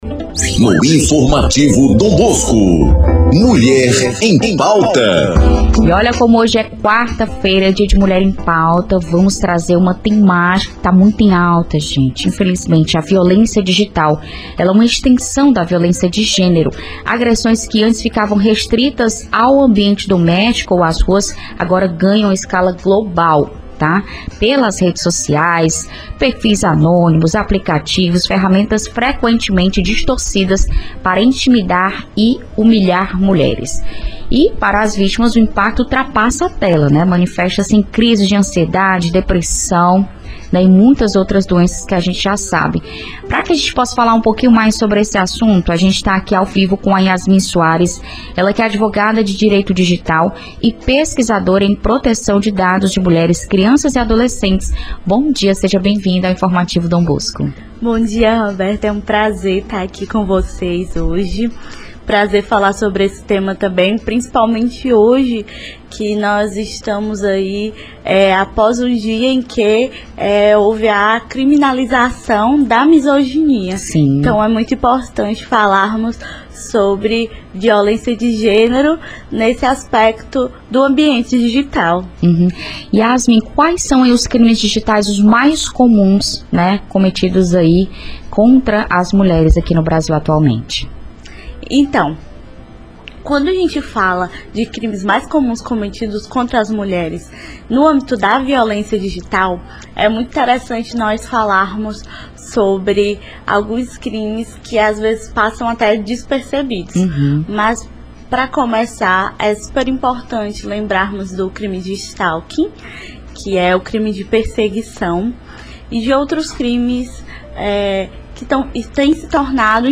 Violência digital: a nova face da agressão contra a mulher; confira entrevista
ENTREVISTA-2503.mp3